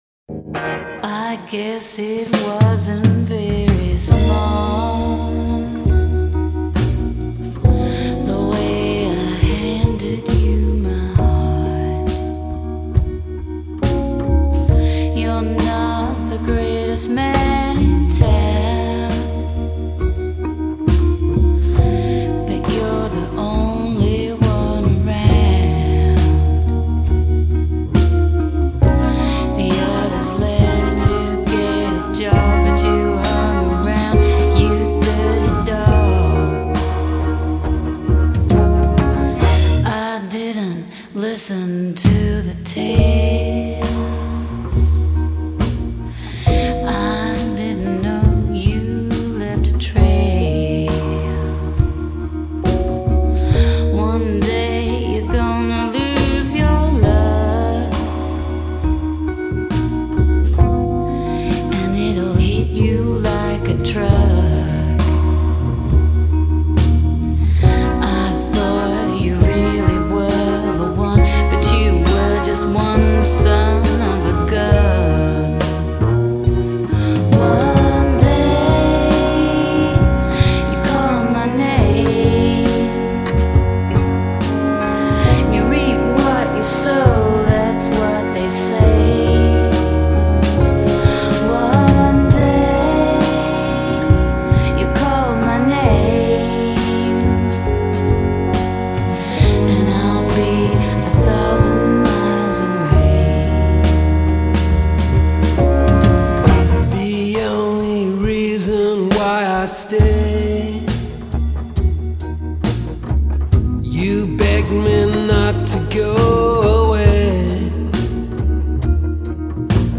The tracks are a strange blend of country and pop;